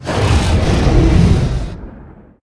CosmicRageSounds / ogg / general / combat / creatures / dragon / he / turn1.ogg